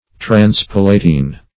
Search Result for " transpalatine" : The Collaborative International Dictionary of English v.0.48: Transpalatine \Trans*pal"a*tine\, a. [Pref. trans- + palatine.]